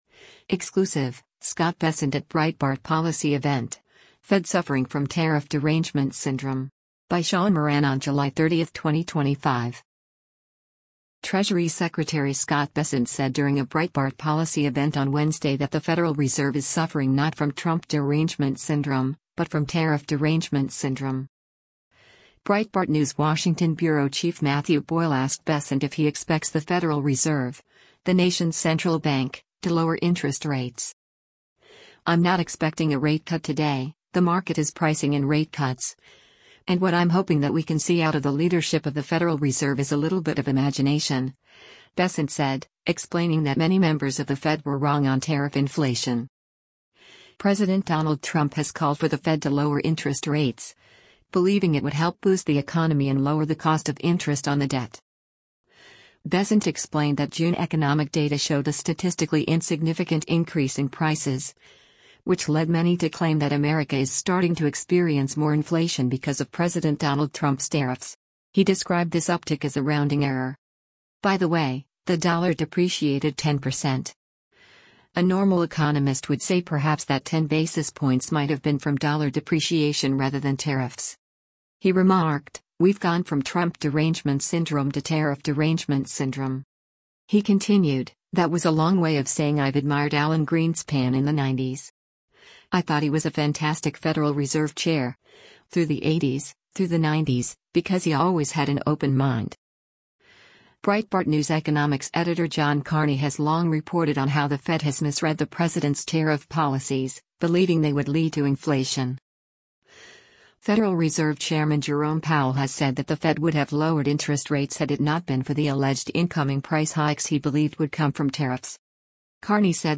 Exclusive--Scott Bessent at Breitbart Policy Event: Fed Suffering from 'Tariff Derangement Syndrome'
Treasury Secretary Scott Bessent said during a Breitbart policy event on Wednesday that the Federal Reserve is suffering not from “Trump Derangement Syndrome,” but from “Tariff Derangement Syndrome.”
Bessent said at the Breitbart policy event, “The lack of open-mindedness has frozen the Fed and I am hoping that they would have a more open mind.”